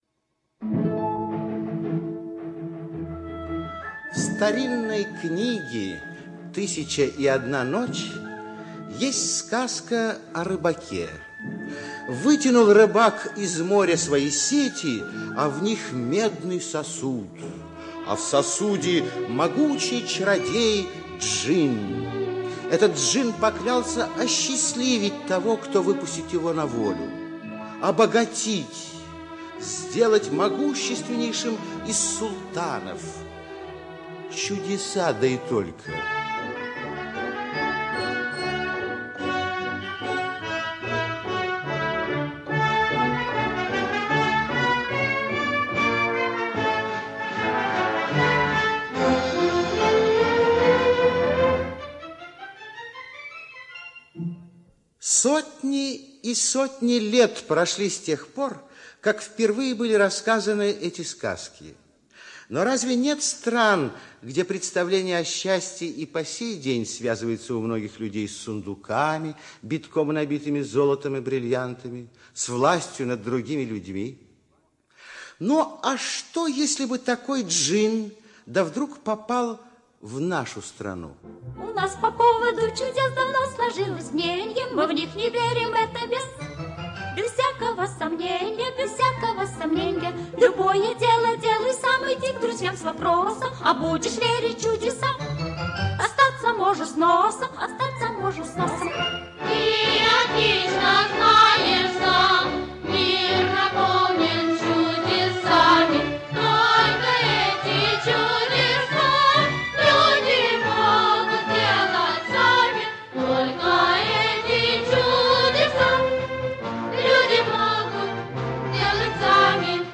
Старик Хоттабыч - аудиосказка Лагина - слушать онлайн